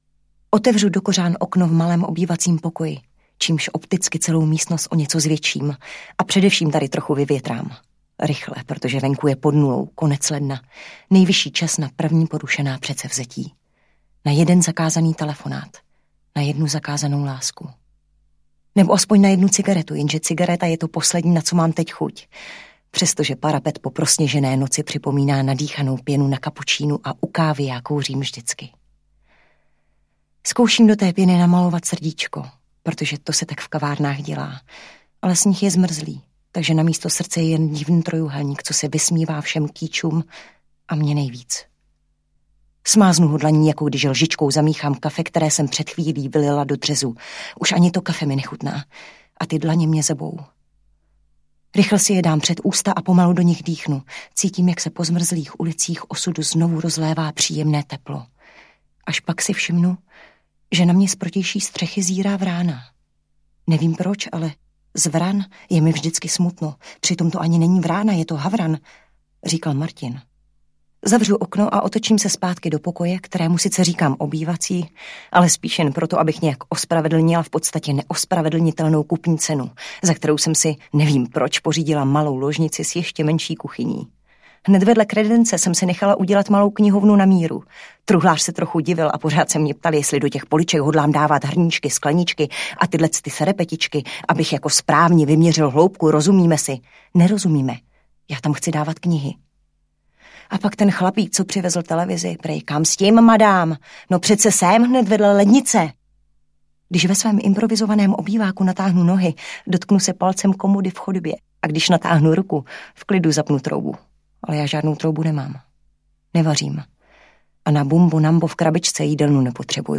Bábovky audiokniha
Ukázka z knihy